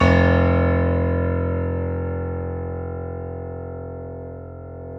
Index of /90_sSampleCDs/Roland L-CD701/KEY_YC7 Piano mf/KEY_mf YC7 Mono